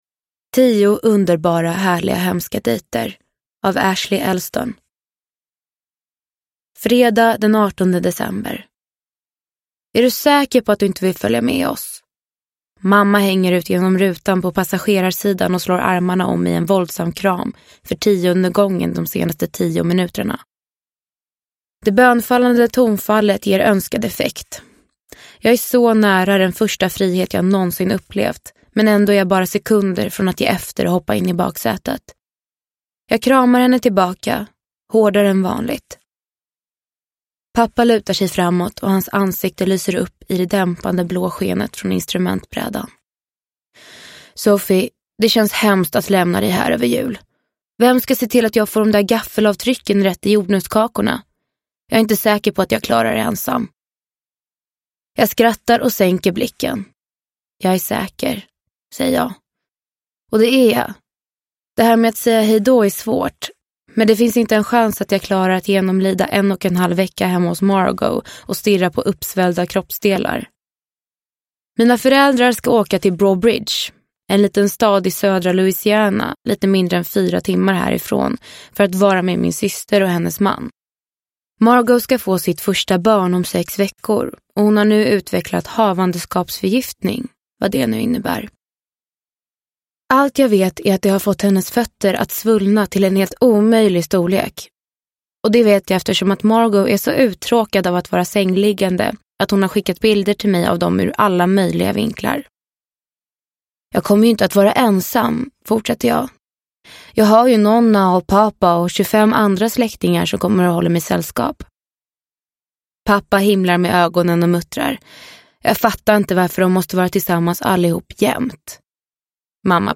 Tio underbara, härliga, hemska dejter – Ljudbok – Laddas ner